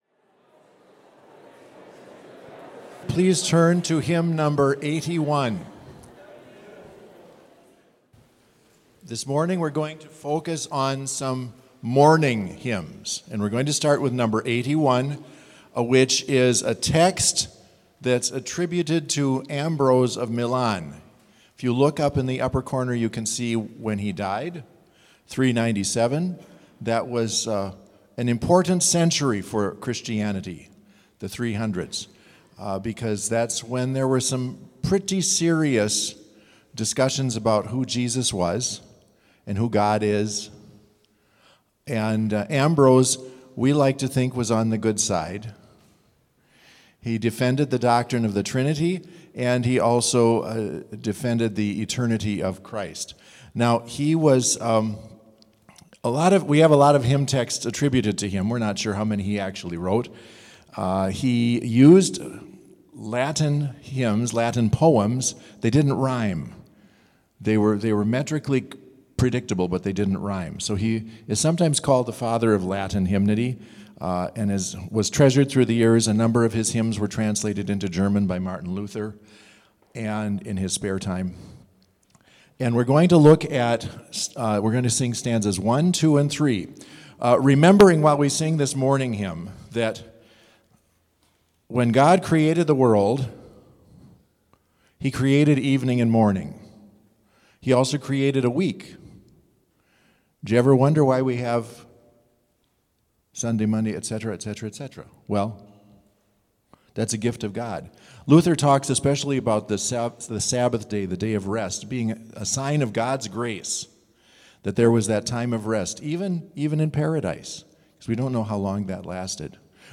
Complete service audio for Chapel - Thursday, October 3, 2024